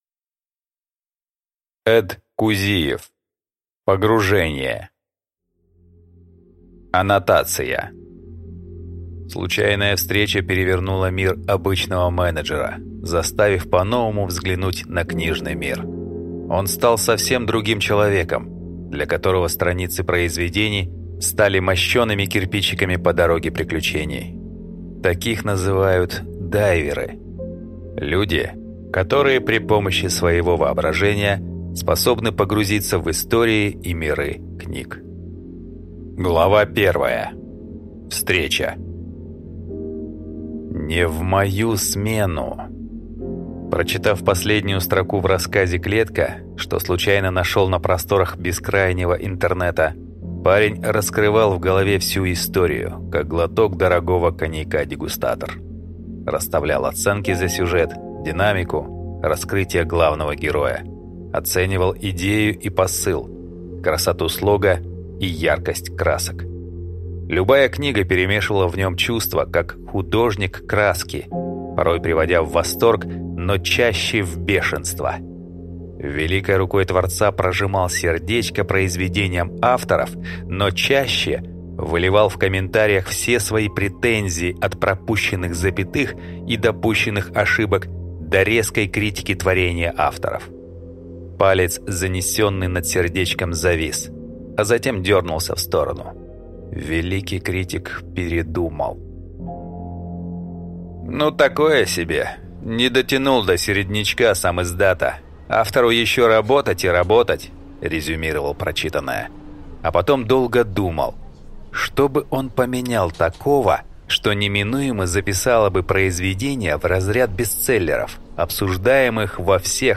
Аудиокнига Погружение